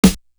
Guns Is Razors Snare.wav